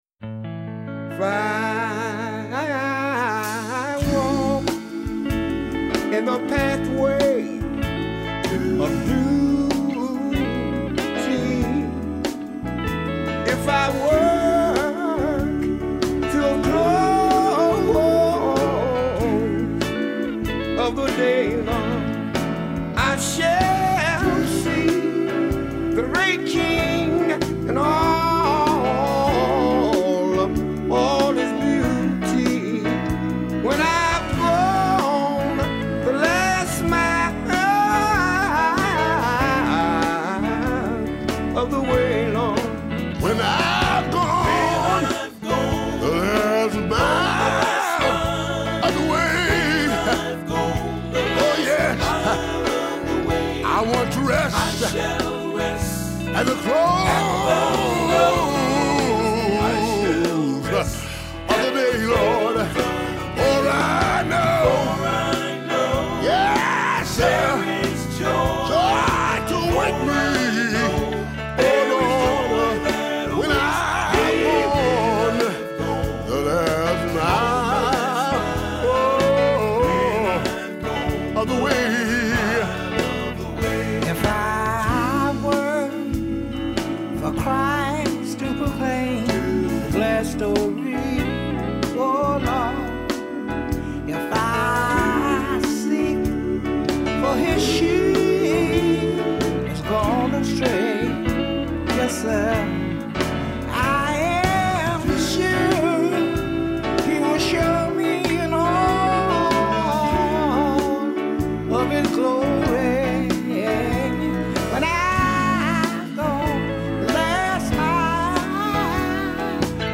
All of the guys contributed to the background vocals.
tight background harmony
drums
Bass Guitar
guitar
Piano & Organ